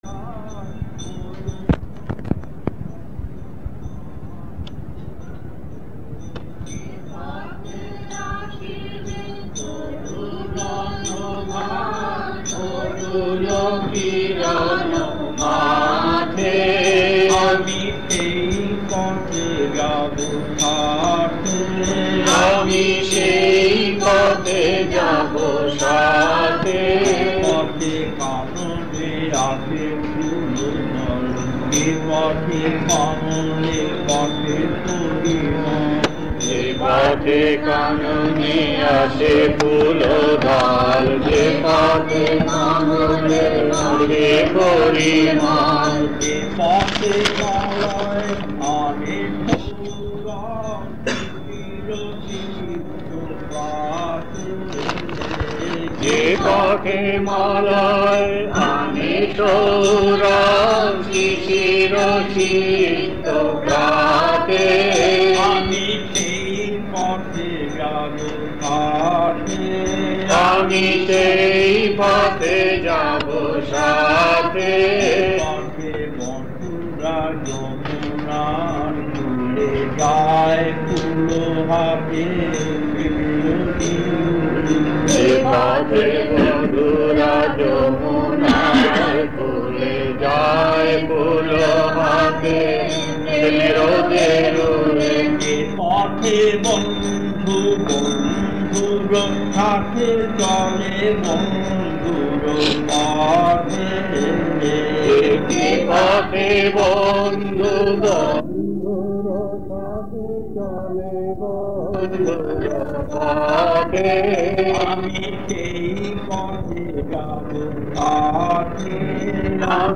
Kirtan19part2.mp3